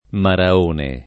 [ mara 1 ne ]